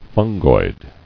[fun·goid]